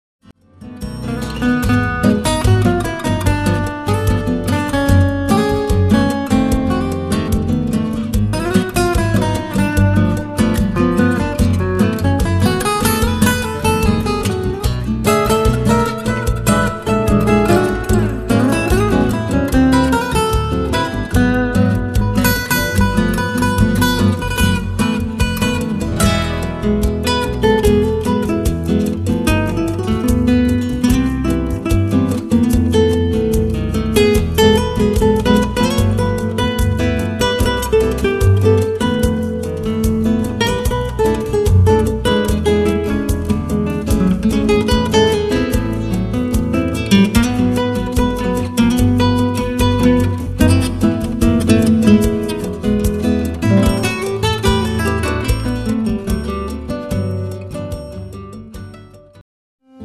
Fingerstyle guiitar
beautiful duet guitar collection of bossa novas and sambas